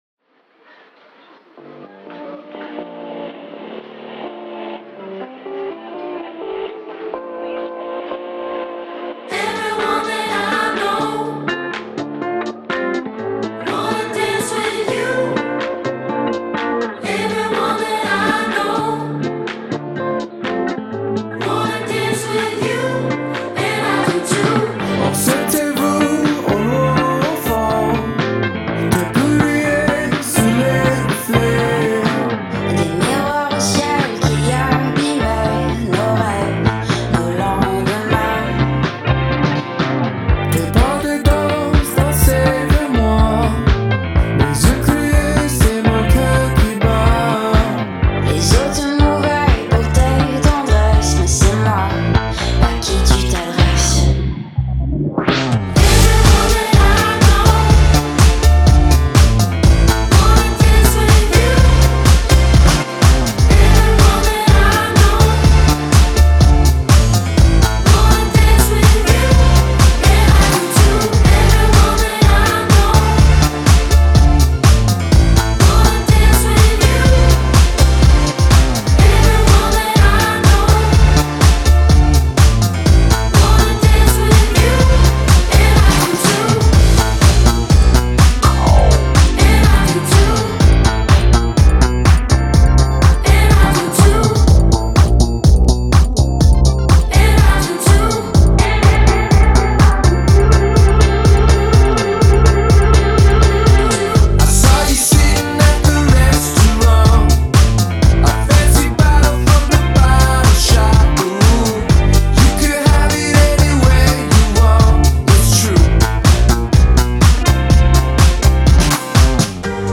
Трек размещён в разделе Зарубежная музыка / Альтернатива.